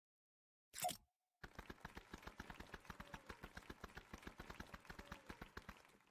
Sound Effects
Fortnite Golf Clap